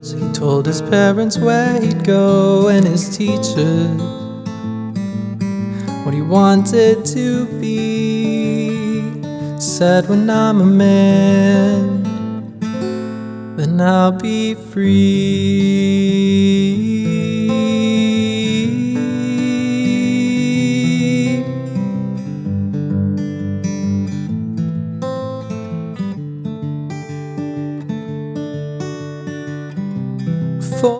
Drums
Bass